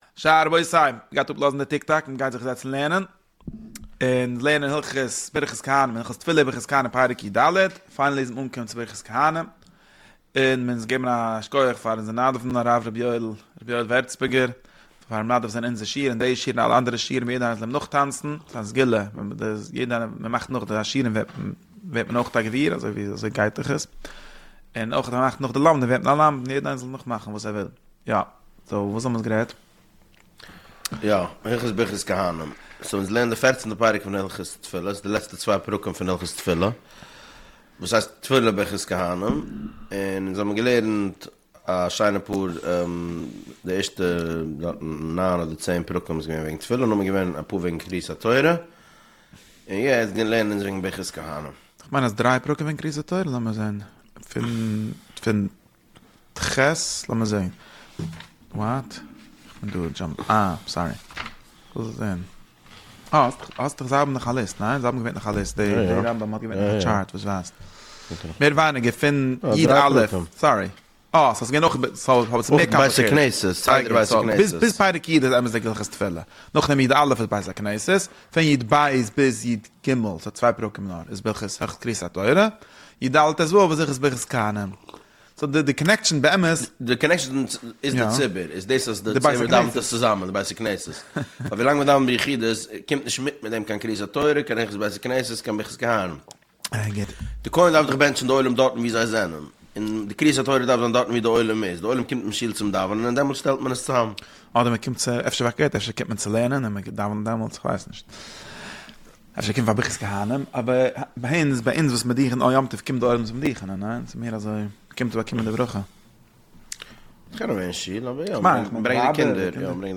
דער שיעור לערנט הלכות ברכת כהנים פון רמב"ם הלכות תפילה פרק י"ד. ס'ווערט באהאנדלט דער גאנצער סדר פון נשיאת כפים - ווען די כהנים גייען ארויף, וויאזוי זיי שטייען, ווי דער שליח ציבור זאגט פאר יעדע ווארט, און די חילוקים צווישן דוכנען אין בית המקדש און אין די גבולין.